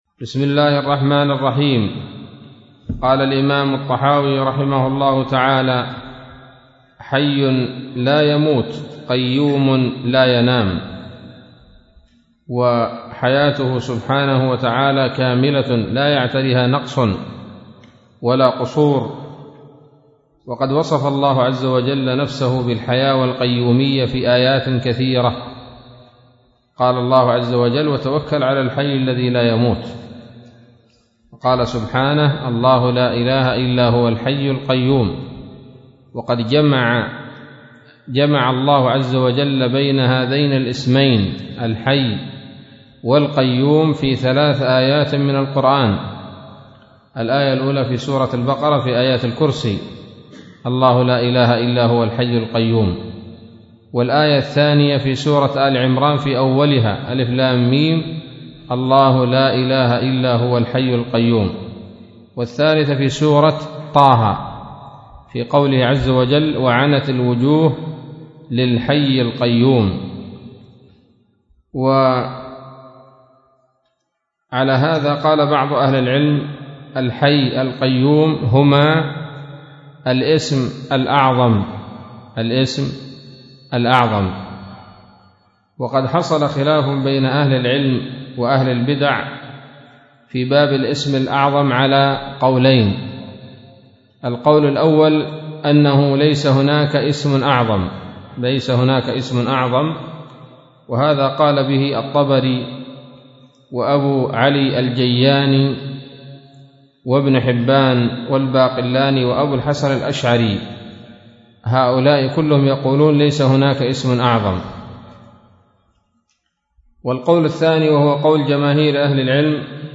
الدرس الخامس من شرح العقيدة الطحاوية